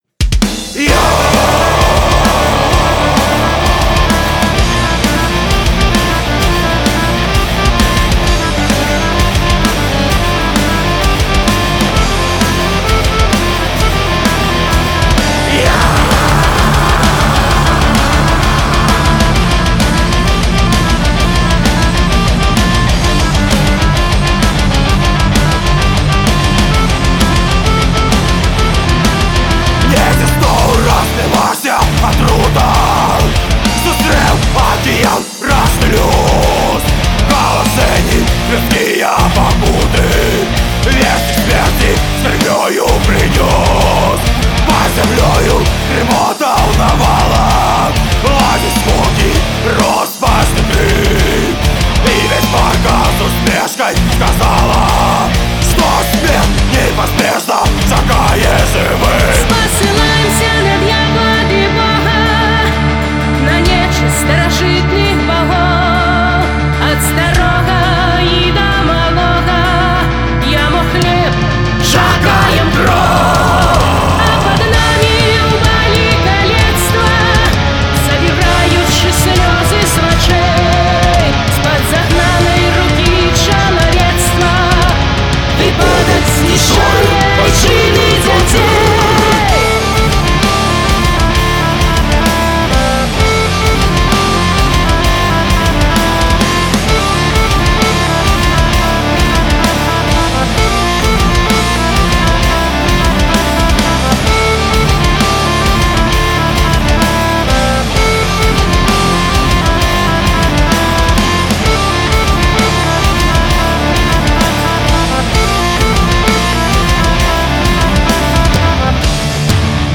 беларускай мэтал-музыкі